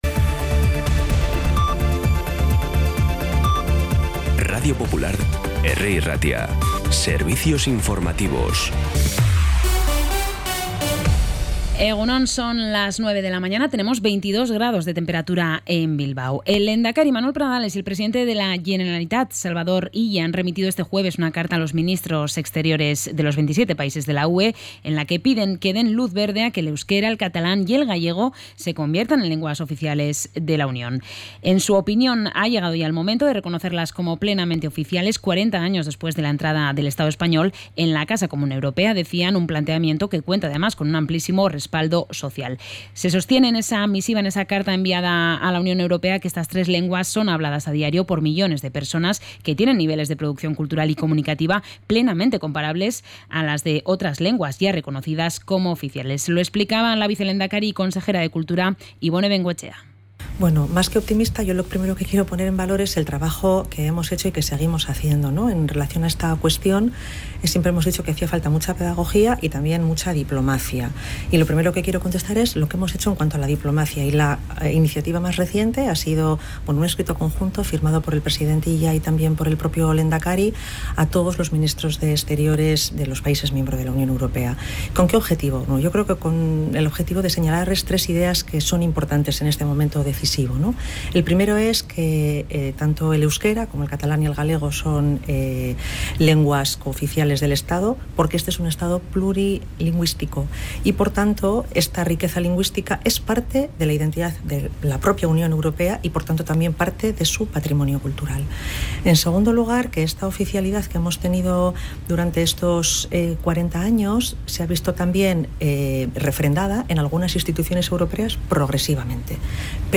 Información y actualidad desde las 9 h de la mañana